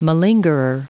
Prononciation du mot malingerer en anglais (fichier audio)
Prononciation du mot : malingerer